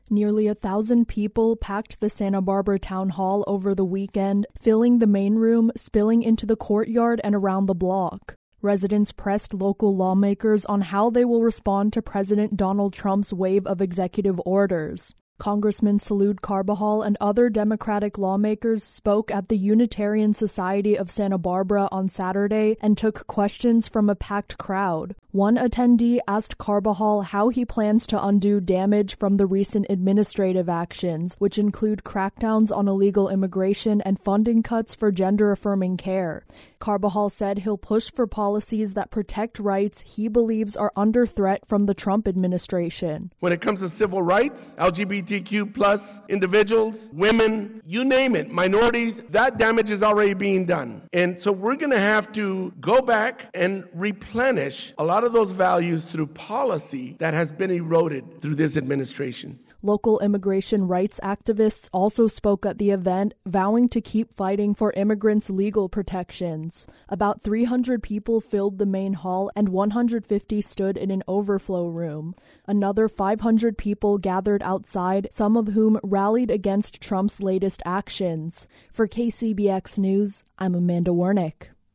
sb-town-hall-web.mp3